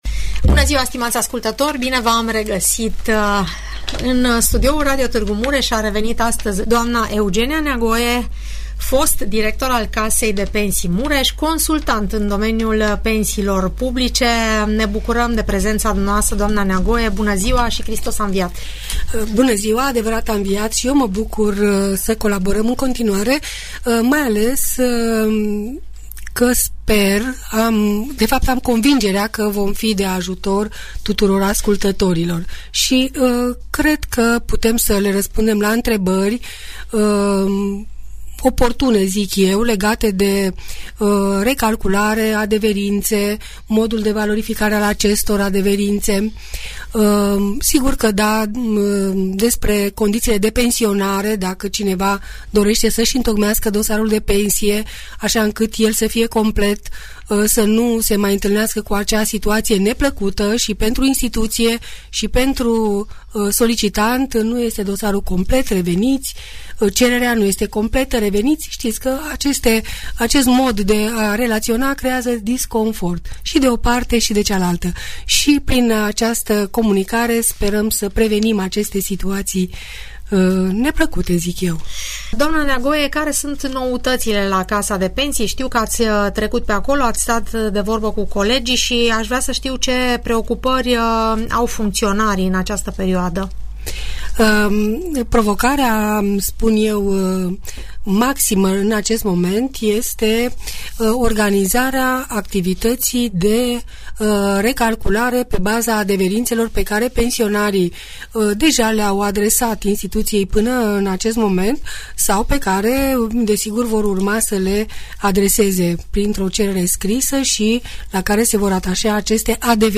Audiență radio cu întrebări și răspunsuri despre toate tipurile de pensii, în emisiunea "Părerea ta" de la Radio Tg Mureș.